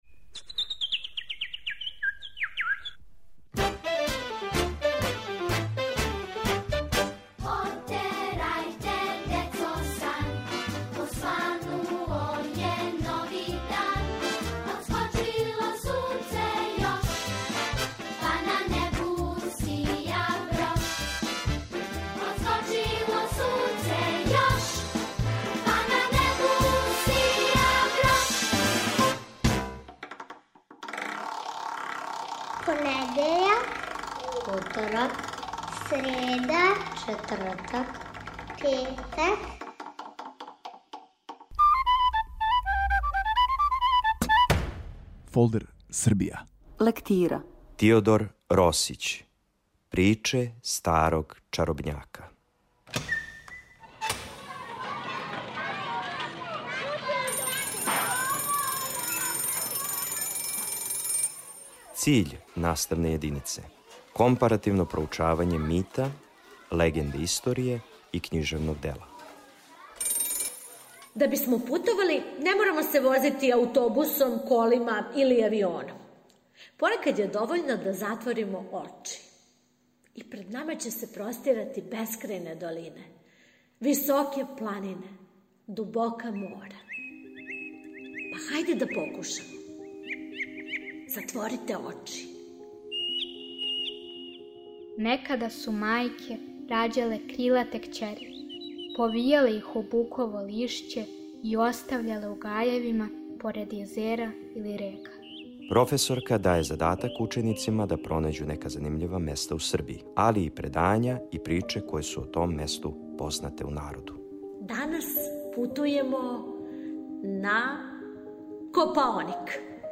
Серијал "Фолдер Србија" води вас на час српског језика и књижености. Присуствујемо лекцији: Тиодор Росић, "Приче старог чаробњака".